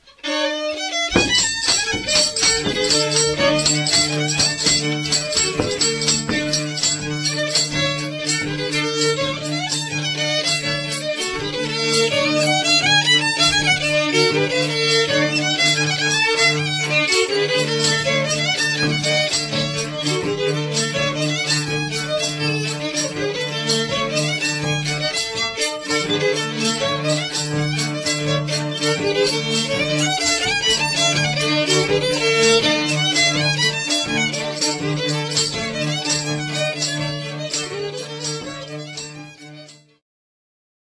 Mazurek (Strzemeszna, Rawskie 1987)
Badania terenowe
skrzypce
basy 3-strunowe
bębenek